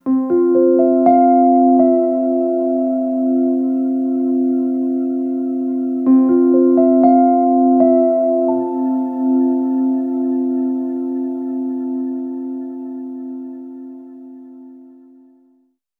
Reverb Piano 11.wav